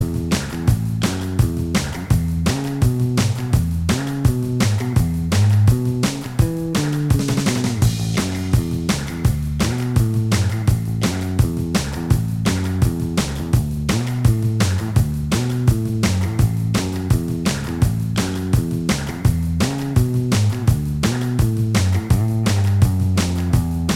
Minus Guitars Rock 2:21 Buy £1.50